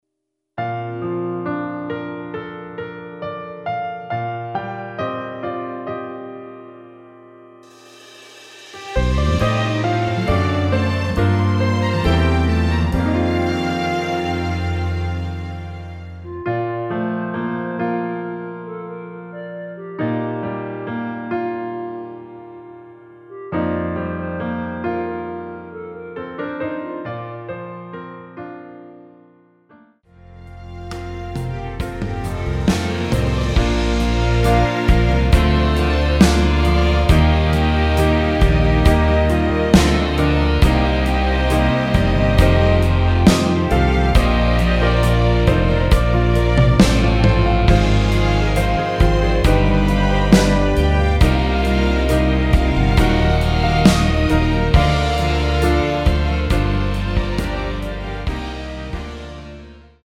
(-1)내린 멜로디 포함된 MR 입니다.(미리듣기 참조)
Bb
◈ 곡명 옆 (-1)은 반음 내림, (+1)은 반음 올림 입니다.
앞부분30초, 뒷부분30초씩 편집해서 올려 드리고 있습니다.